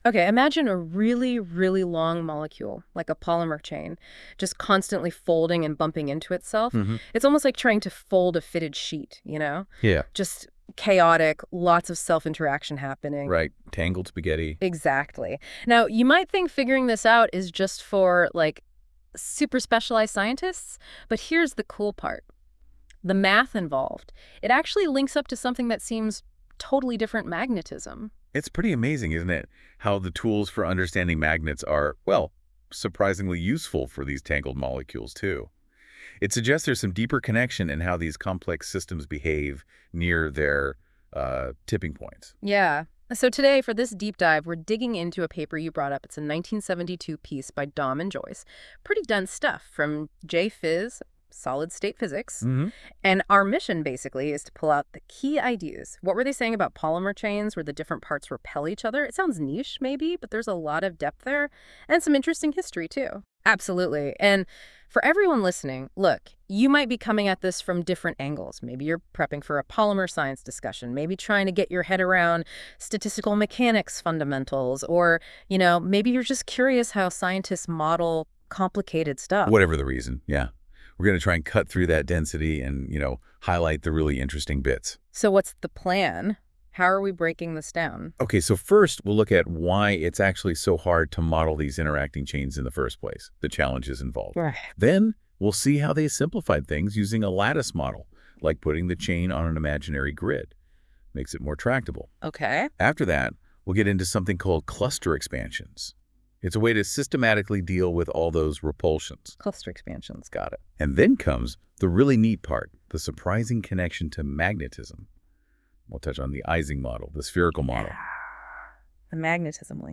using NotebookLM powered by Google Gemini.)